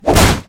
Wood Smash.mp3